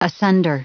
Prononciation du mot asunder en anglais (fichier audio)
Prononciation du mot : asunder